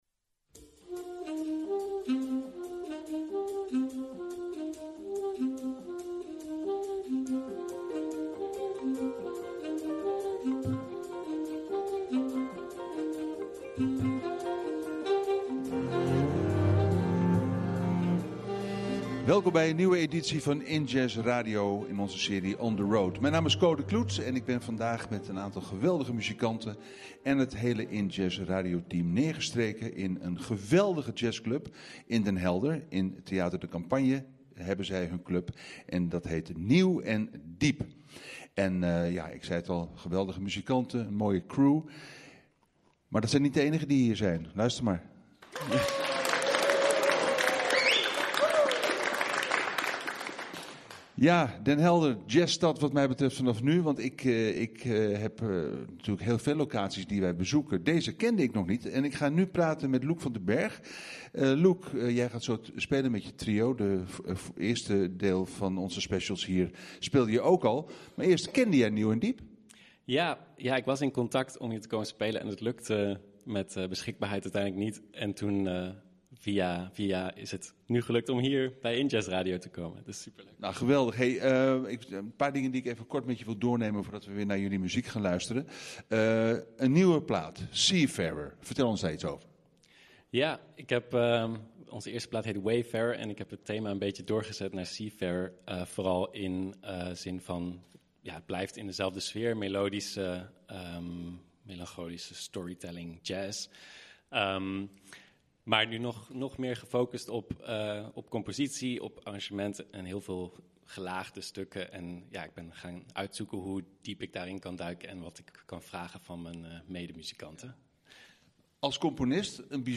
Centraal staat de promotie van jazz en beyond. Nu deel 2 van het bezoek aan jazzclub Nieuw & Diep in Den Helder.